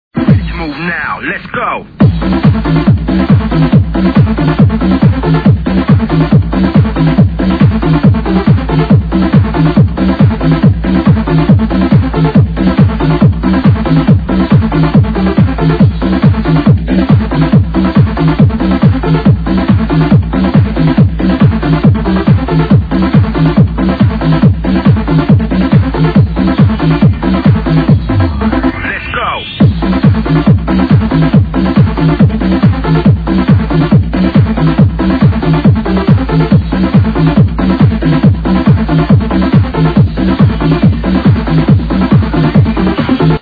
Another party tune there i guess, could use some ID.
highly commercial